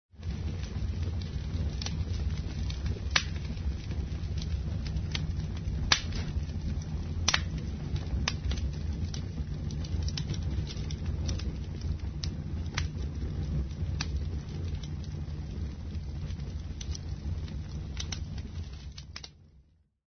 CREPITAR FIRE FUEGO LLAMAS
Ambient sound effects
Descargar EFECTO DE SONIDO DE AMBIENTE CREPITAR FIRE FUEGO LLAMAS - Tono móvil
crepitar__Fire_fuego_llamas.mp3